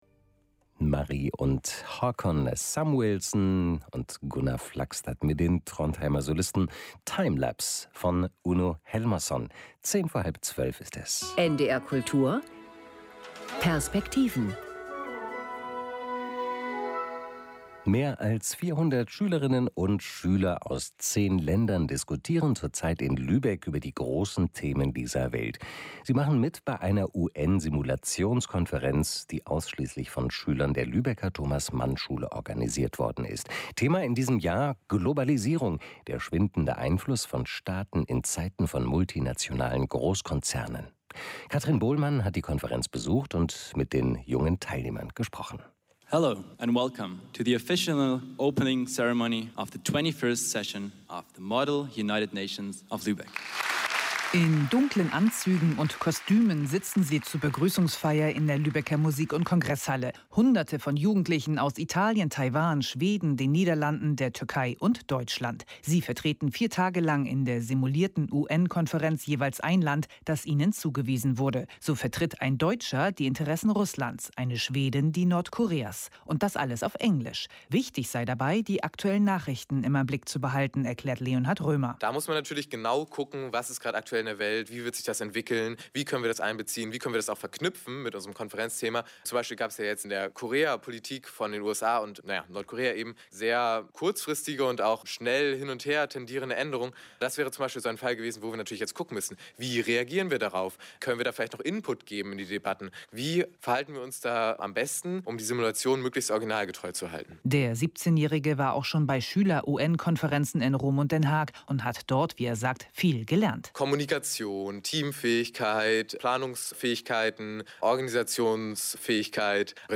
MUNOL 2018 – Wer einen Eindruck von der diesjährigen Simulation der Vereinten Nationen in englischer Sprache gewinnen möchte, an der Delegationen aus über 30 Nationen teilgenommen haben, hat Gelegenheit dazu mit dem Hörfunkbeitrag des Norddeutschen Rundfunks.